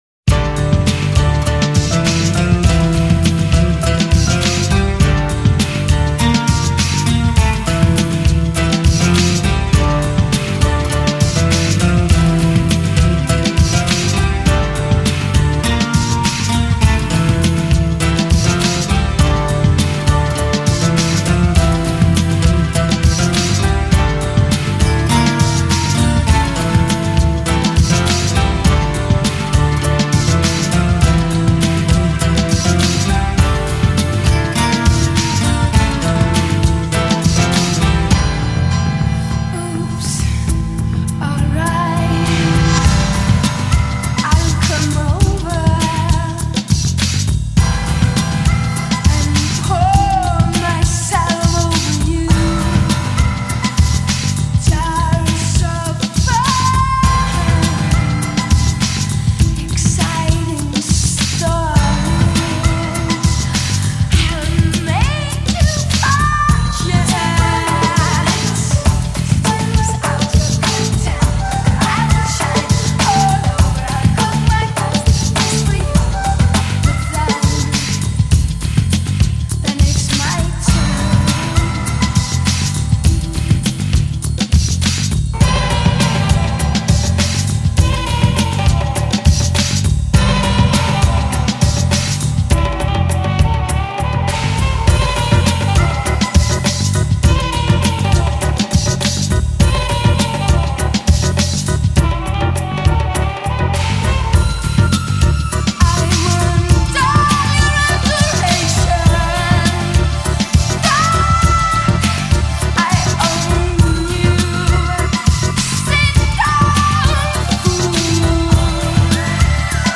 electronic group